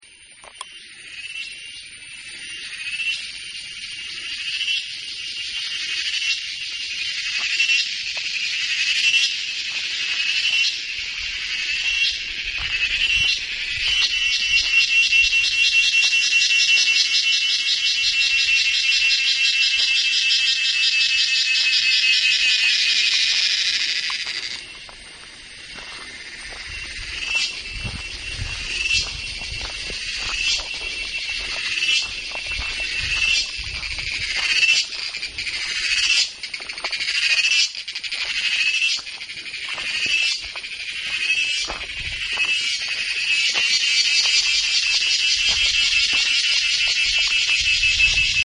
Sound_of_Forest_(Mookambika_wildlife_sanctuary).ogg